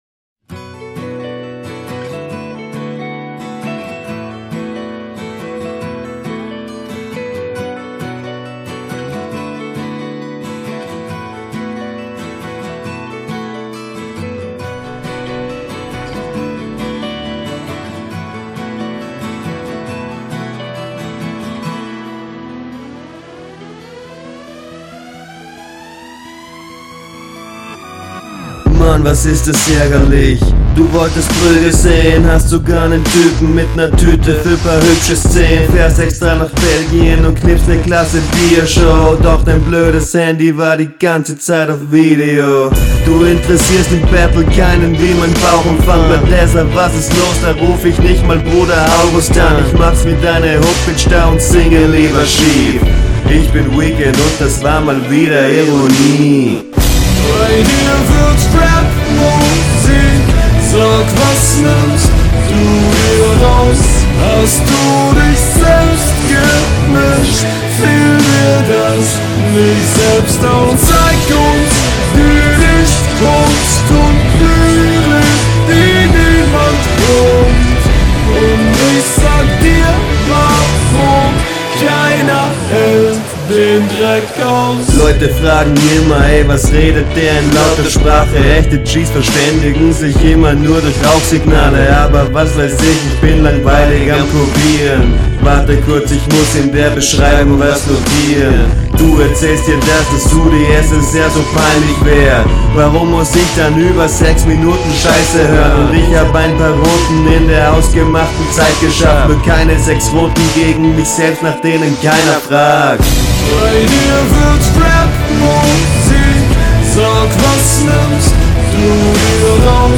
Stärker als dein Gegner . starke Hook .